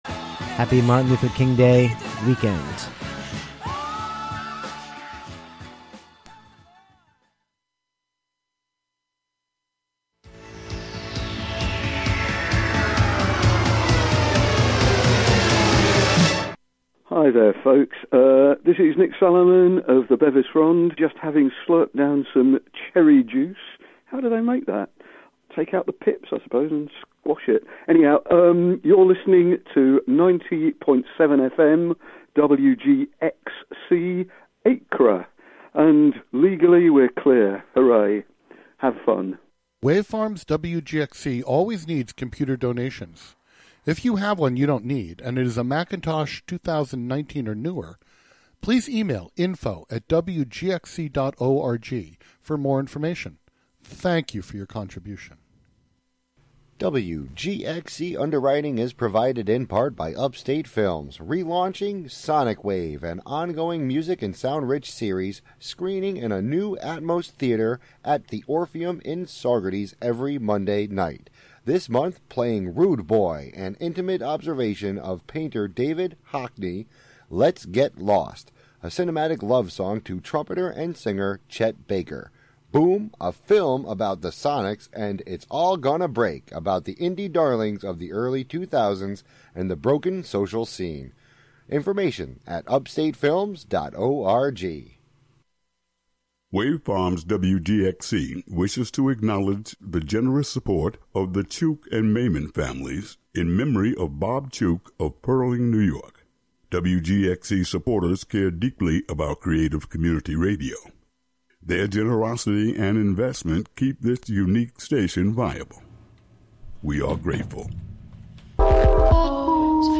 Maybe it's ambient? The default setting is "jazz".